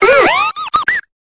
Cri de Baudrive dans Pokémon Épée et Bouclier.